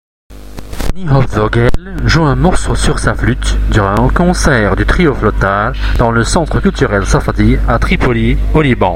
Podcast_Flute_Traversiere.mp3 (142.46 Ko)